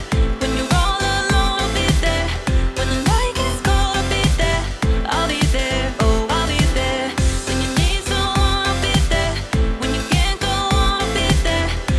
I used 8x oversampling for all examples and left the output gain control untouched in all examples for comparison purposes.
AI Loudener (85% Transparent, 15% Warm) Drive 50% resulted in -13.7 LUFS